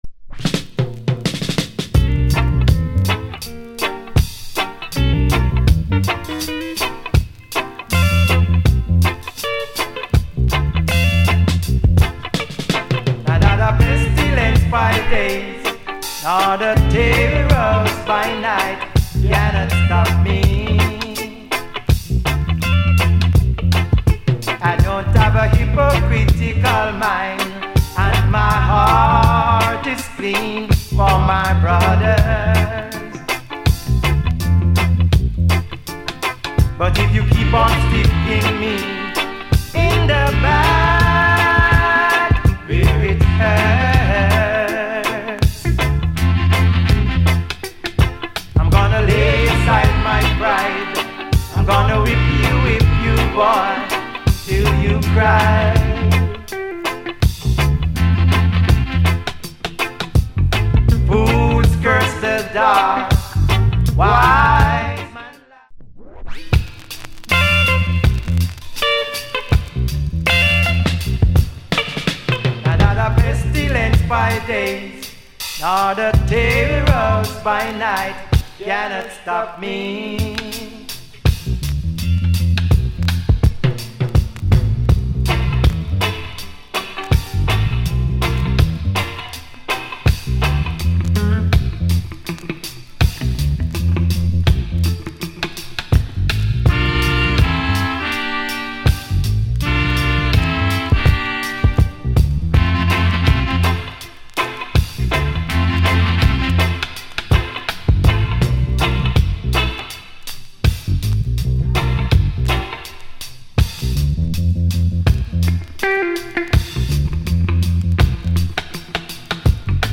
Male Vocal Group Vocal Condition EX Soundclip